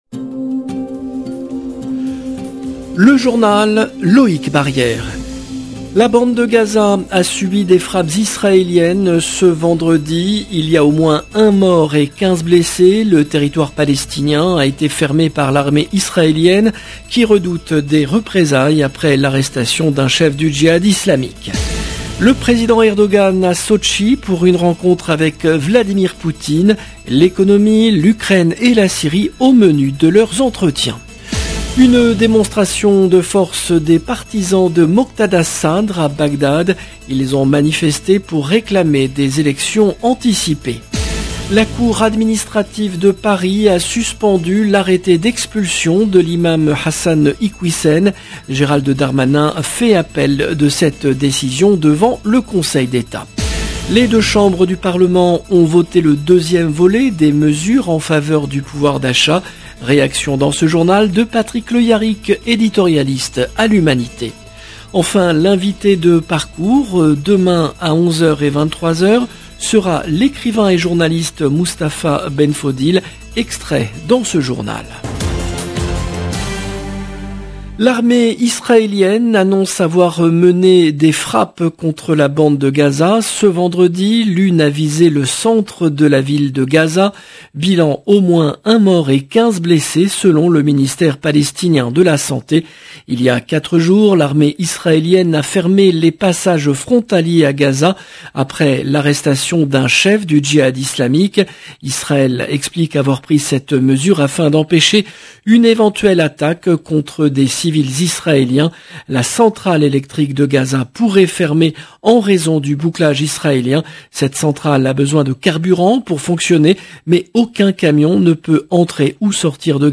LE JOURNAL EN LANGUE FRANÇAISE